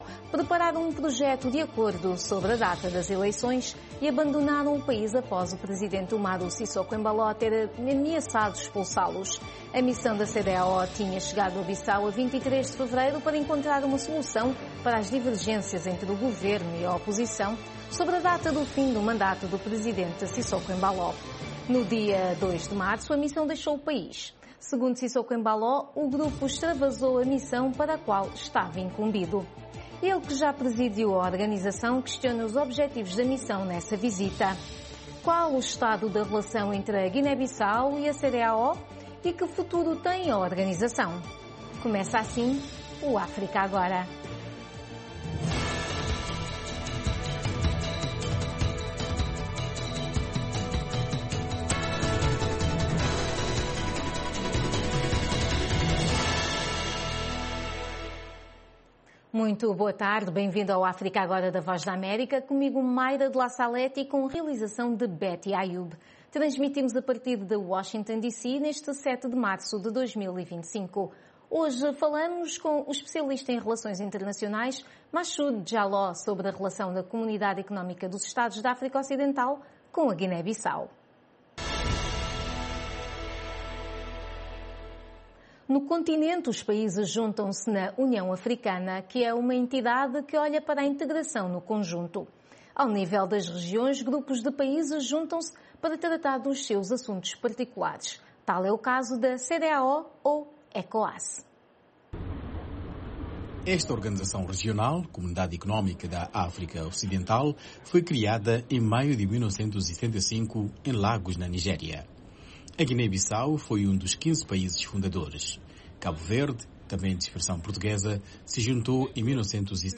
África Agora, o espaço que dá voz às suas preocupaçōes. Especialistas convidados da VOA irão comentar... com a moderação da Voz da América. Um debate sobre temas actuais da África Lusófona.